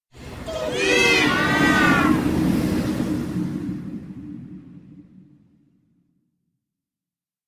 Звуки призраков
На этой странице собраны пугающие звуки призраков и привидений, которые помогут вам создать таинственную или жуткую атмосферу.